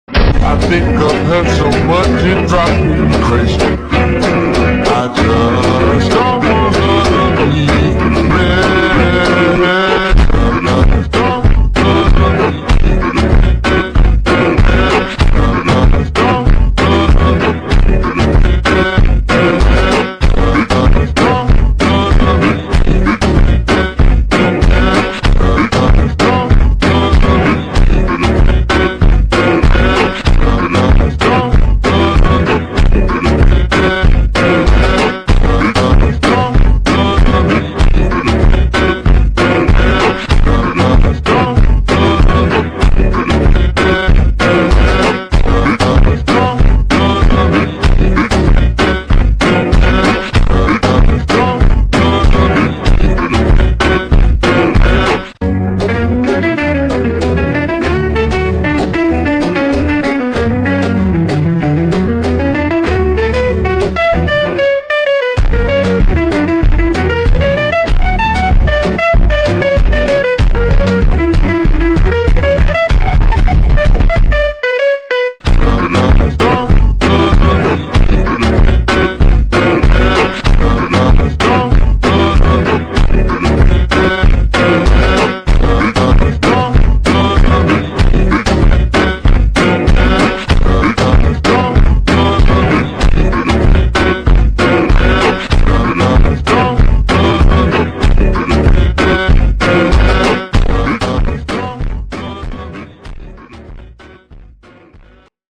Трек подвергнутый эффекту замедления, что придаёт
ему совершенно новое звучание. Глубокие, мощные
басы создают плотную звуковую картину,